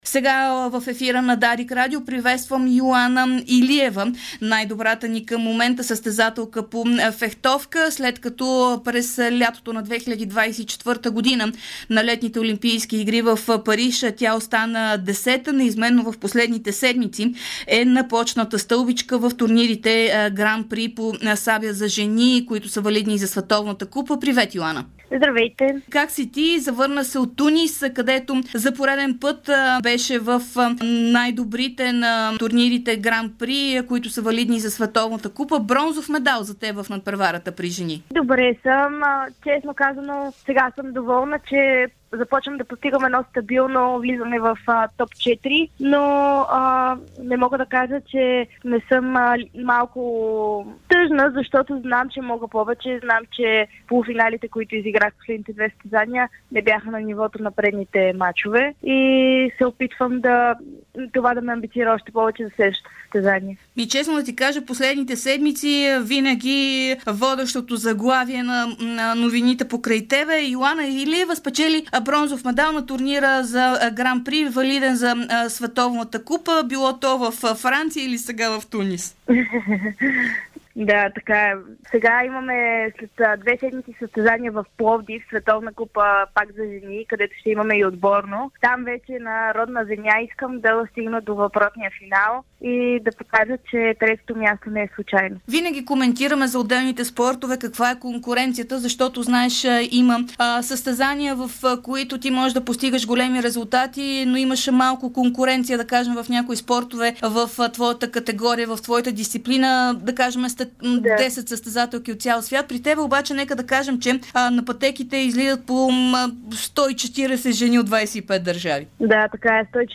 Най-успешната ни в момента състезателка по фехтовка Йоана Илиева говори пред Дарик радио. Преди два дни в Тунис тя спечели бронзов медал в турнира на сабя за жени от веригата Гран При - валидна за Световна купа, където на пътеките излязоха 139 жени от 25 държави. малко преди това стори същото и във Франция.